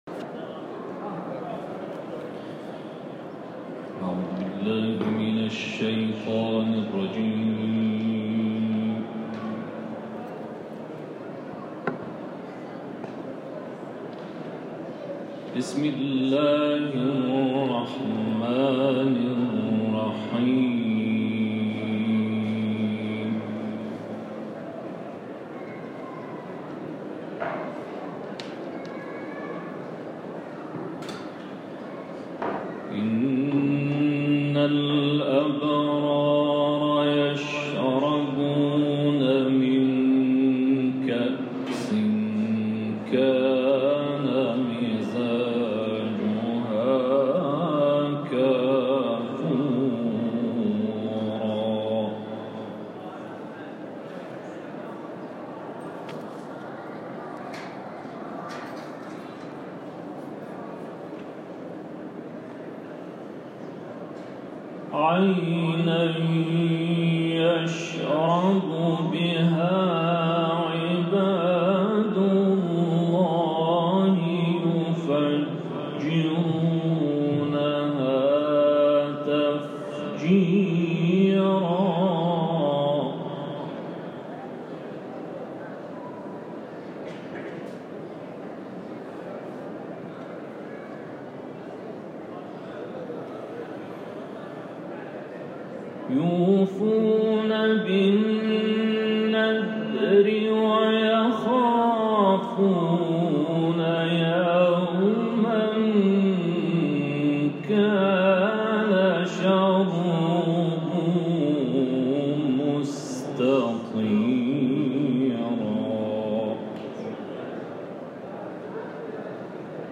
تلاوت آیات 5 تا 18 سوره مبارکه انسان در حرم مطهر حضرت معصومه(س)